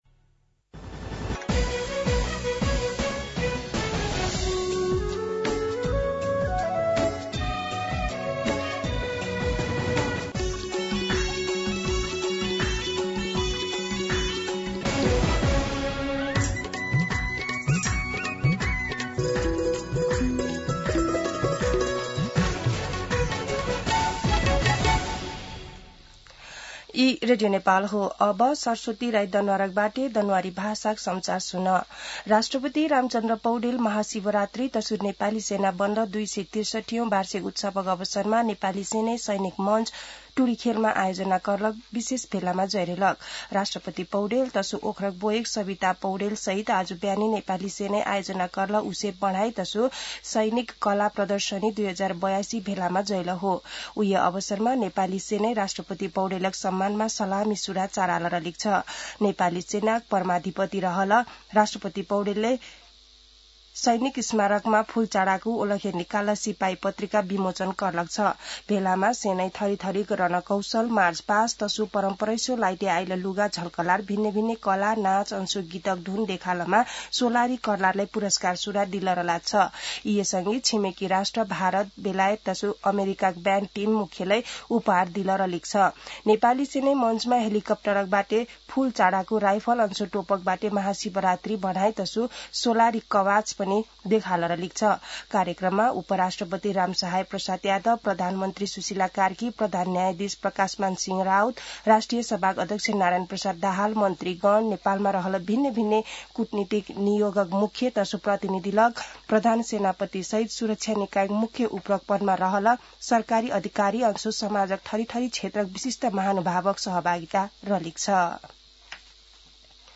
An online outlet of Nepal's national radio broadcaster
दनुवार भाषामा समाचार : ३ फागुन , २०८२
Danuwar-News-03.mp3